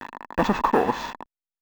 TANKmove1.wav